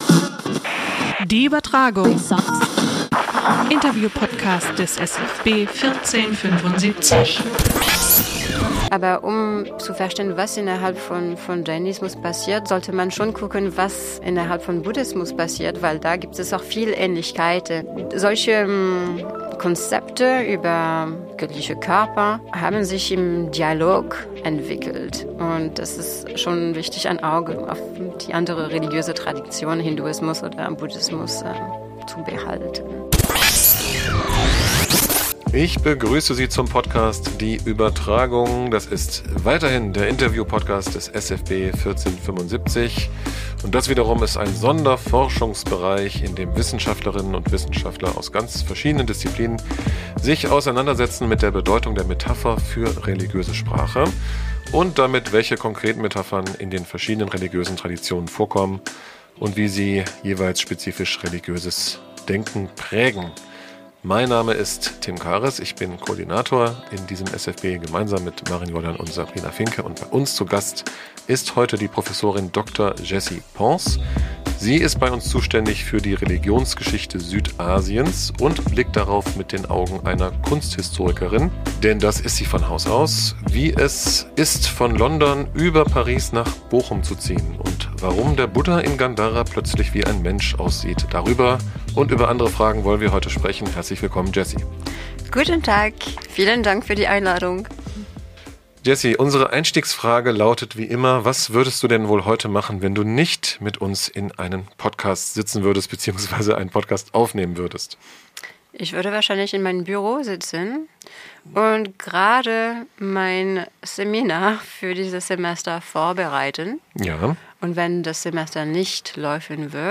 Interview-Podcast des SFB 1475 Podcast